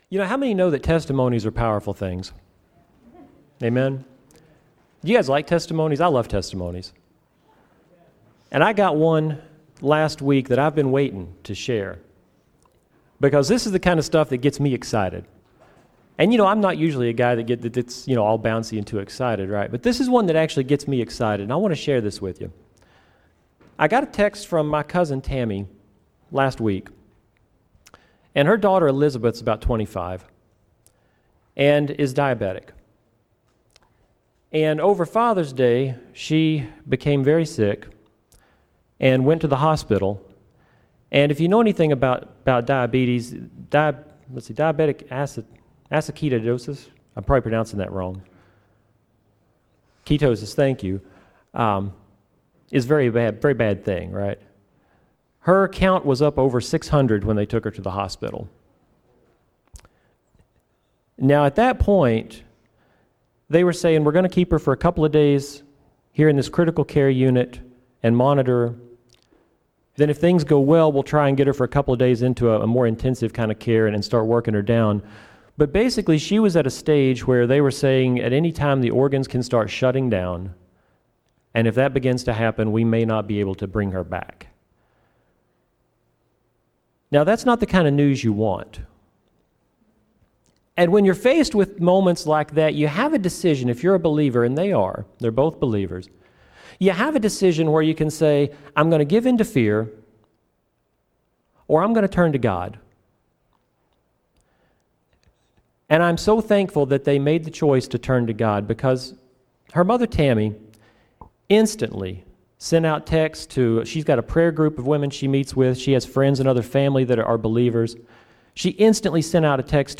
Sermon Notes on YouVersion: